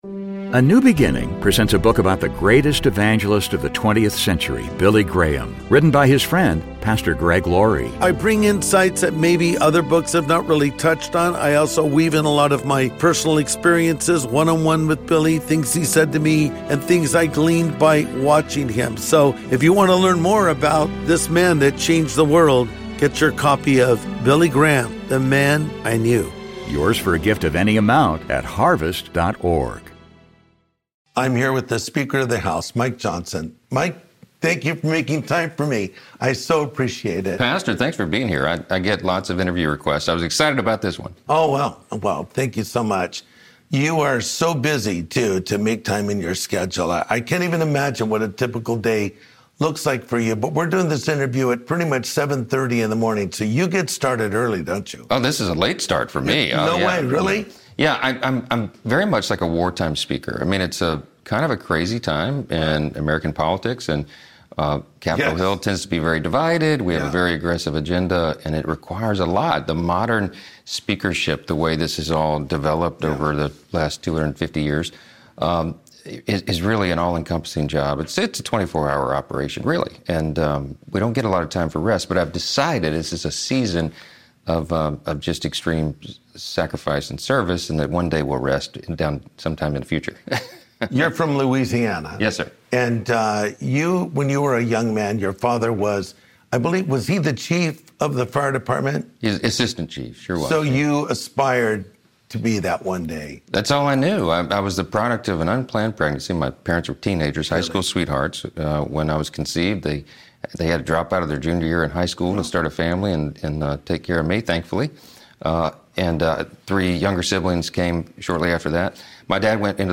Godly Living as a Washington Politician and Talking Faith with Trump (Guest: Speaker Mike Johnson)